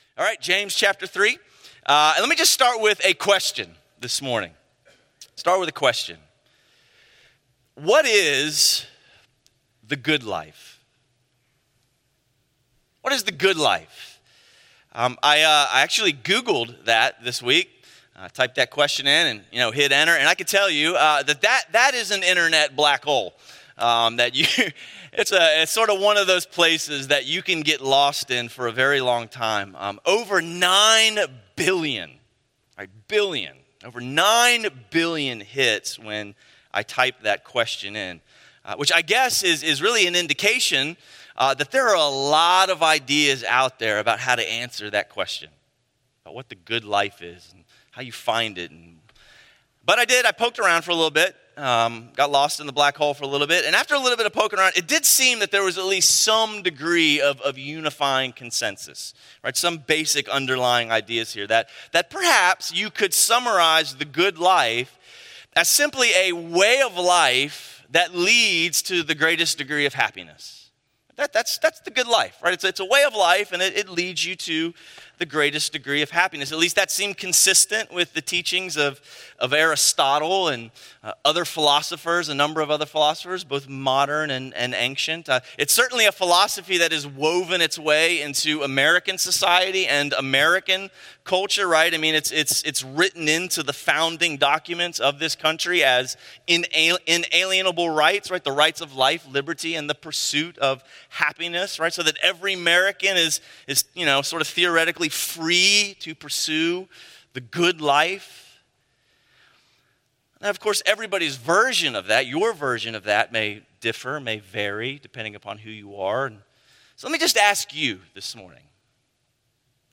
A message from the series "No Other God."